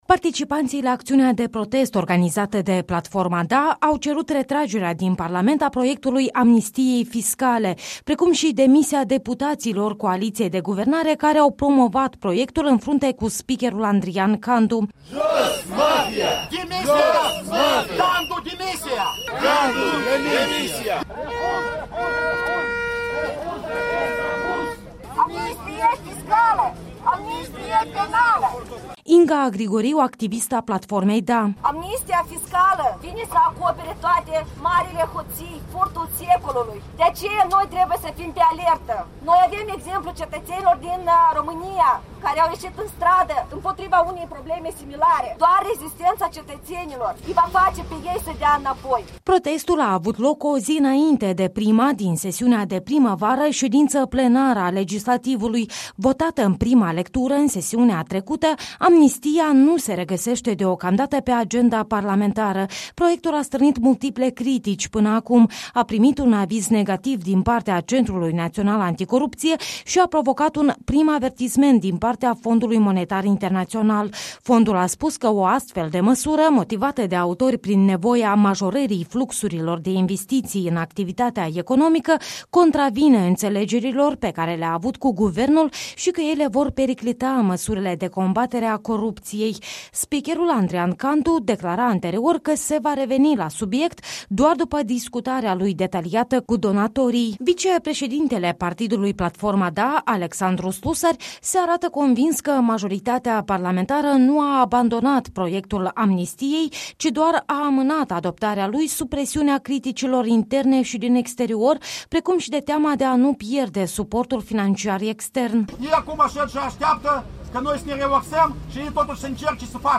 Ei au scandat, între altele, „Jos Mafia!”, „Candu, demisia!”, „Amnistia fiscală, amnistia penală!”.